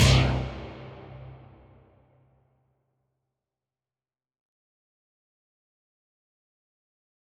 MDMV3 - Hit 8.wav